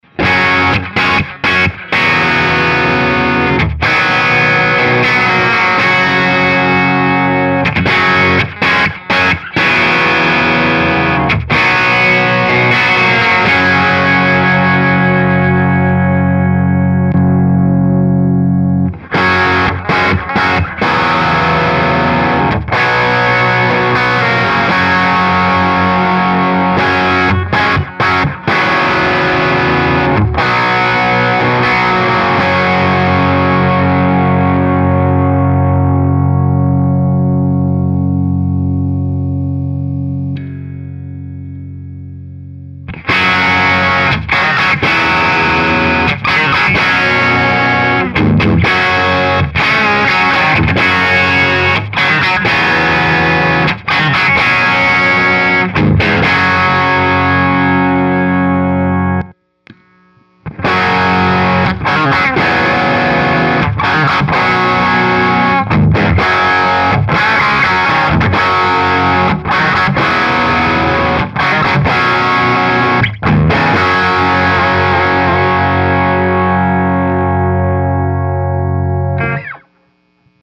Quick demo of parallel vs. series wiring on a Duncan Hot Rails Strat bridge pickup.
Next I used the same clip but added a basic guitar amp plugin to simulate what an amp does. Notice the difference is not so dramatic, the crunch of the “amp” helps the series tone be less muddy. Parallel first and then it alternates.
Amp plugin added:
HotRailsDirectParallelSeries.mp3